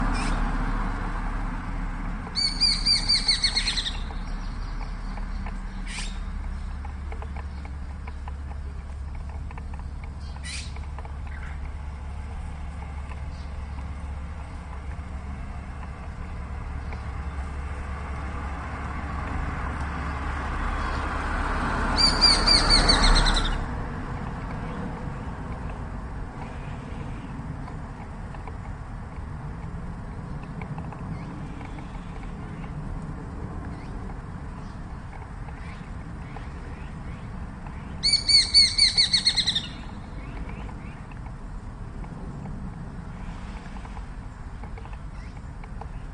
雀鹰叫声音频